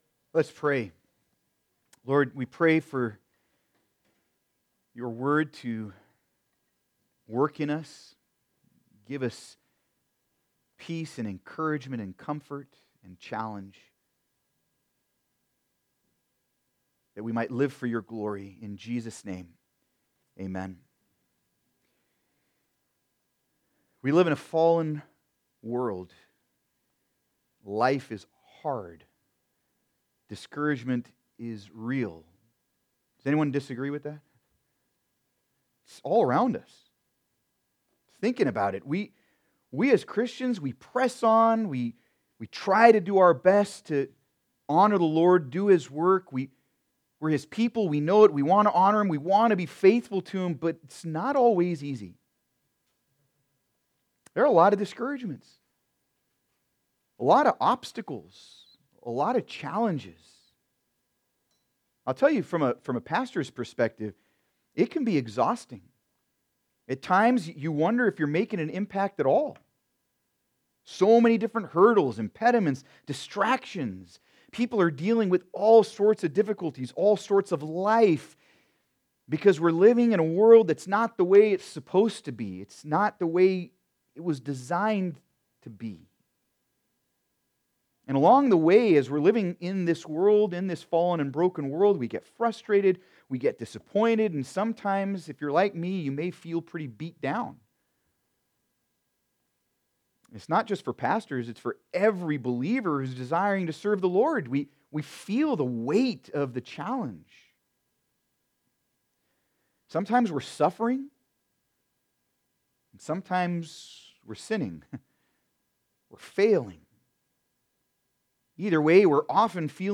Sermon on 2 Timothy 1:1-7
Service Type: Sunday Service